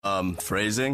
Archer delivers one of his many catchphrases.